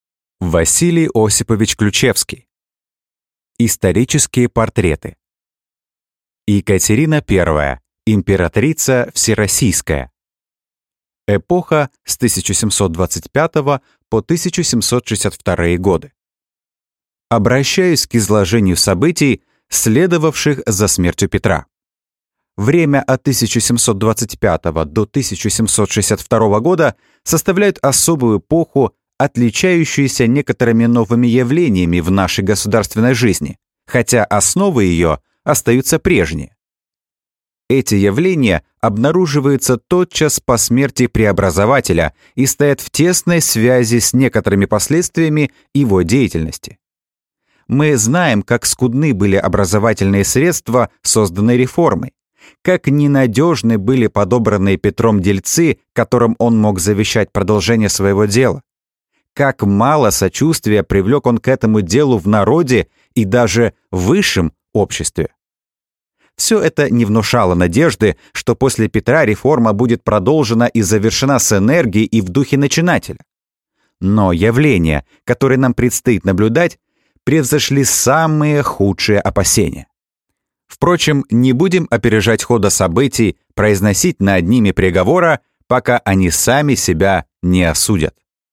Аудиокнига Екатерина I | Библиотека аудиокниг